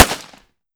fps_project_1/45 ACP 1911 Pistol - Gunshot A 002.wav at 9263e891e616c752ca3077a154f4c1893f470cc0